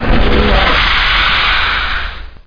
aliendoor3.mp3